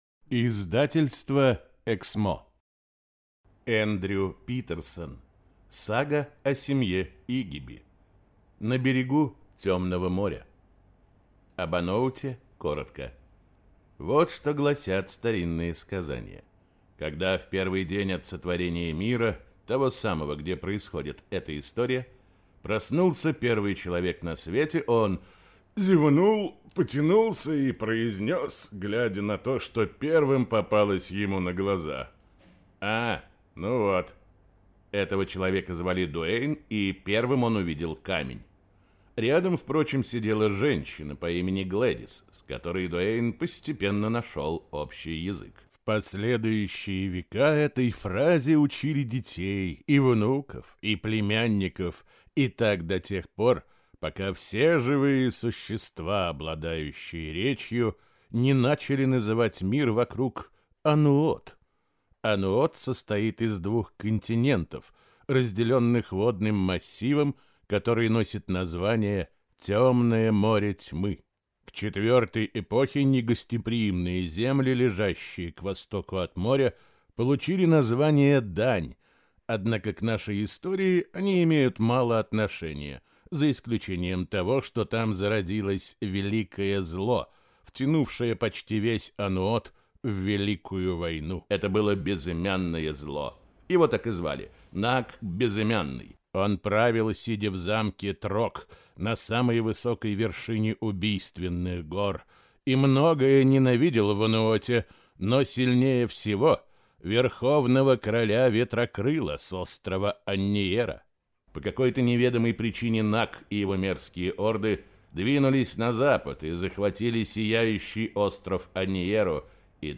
Аудиокнига На берегу Тёмного моря | Библиотека аудиокниг